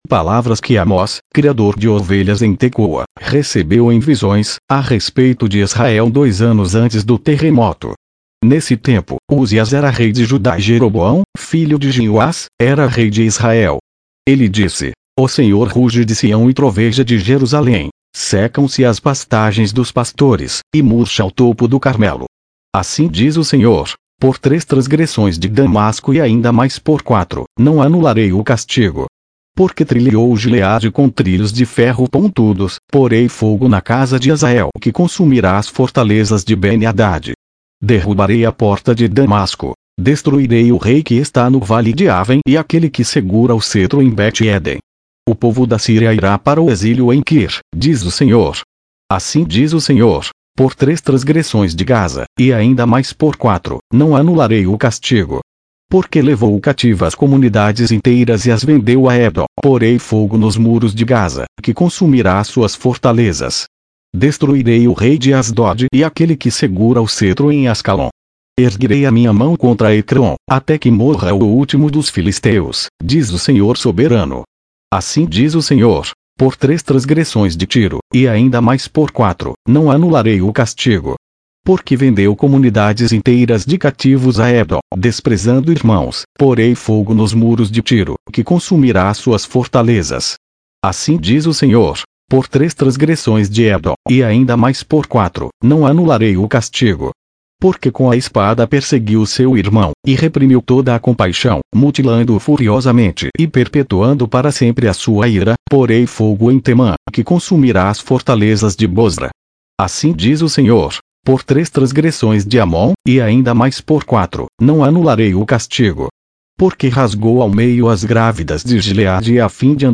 Leitura na versão Nova Versão Internacional - Português